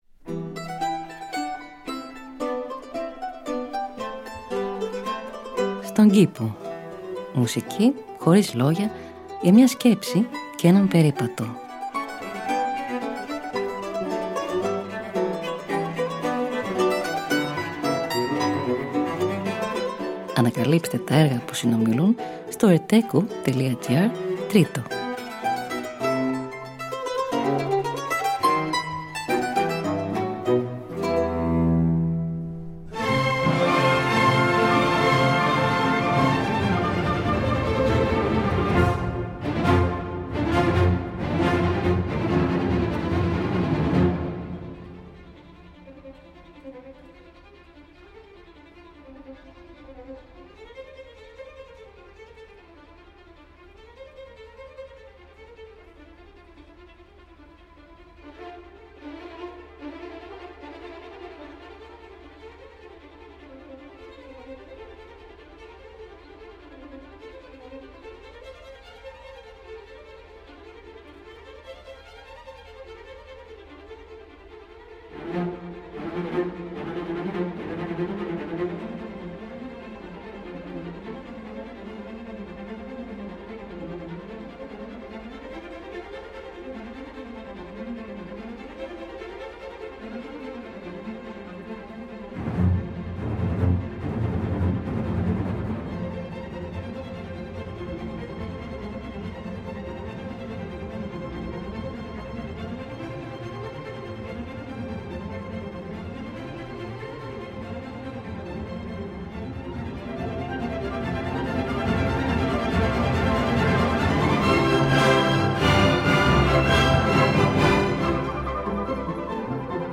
Μουσική Χωρίς Λόγια για μια Σκέψη και έναν Περίπατο.
Allegro – Arrange for mandolin and continuo: Avi Avital